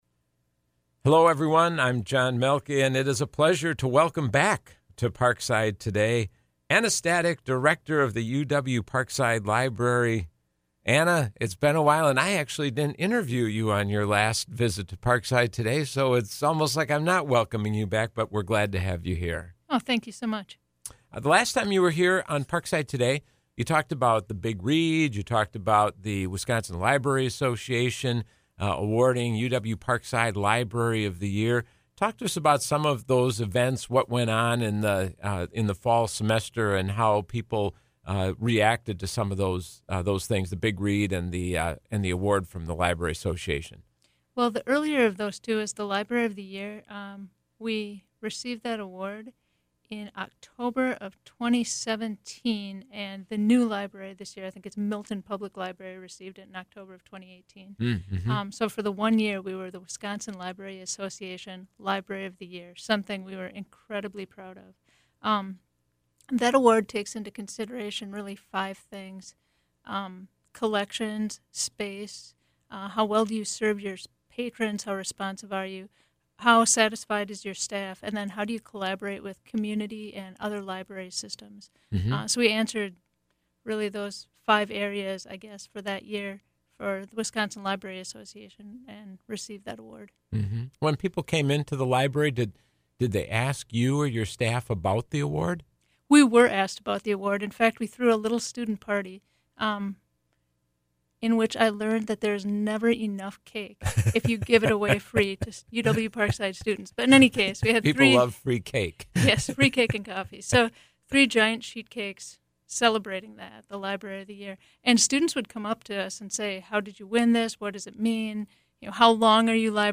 This interview originally aired on Tuesday, Jan. 22, on WIPZ 101.5 FM at 4 p.m.